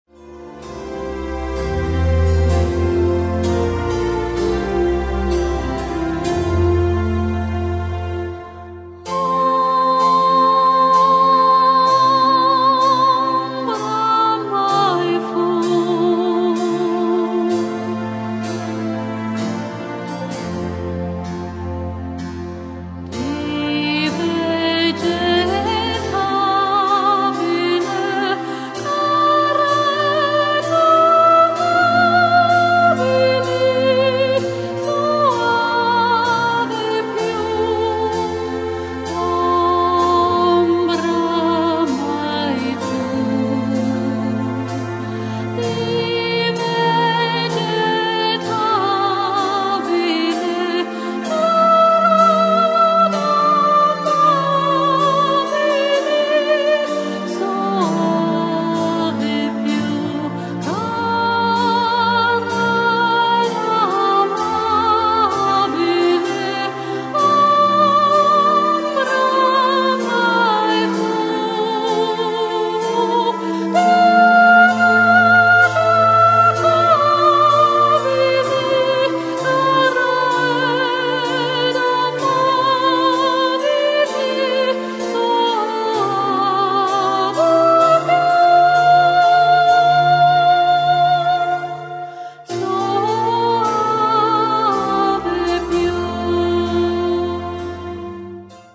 Sängerin Berlin